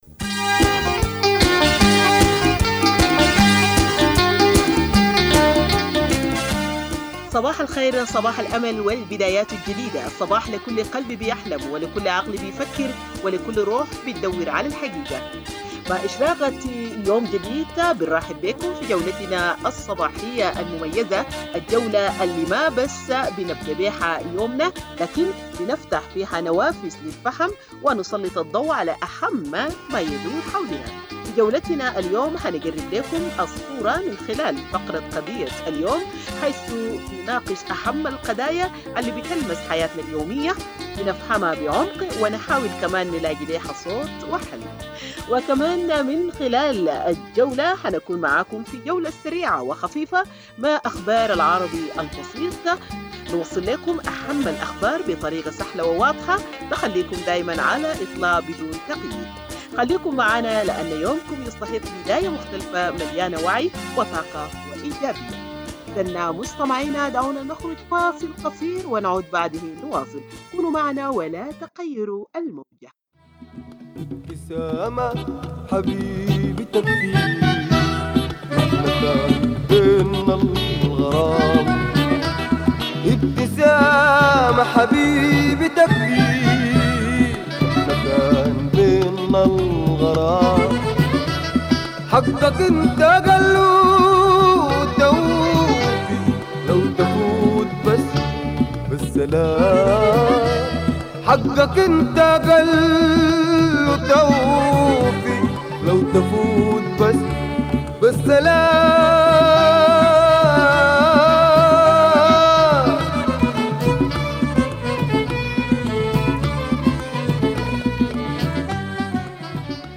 Morning Broadcast 15 April - Radio Tamazuj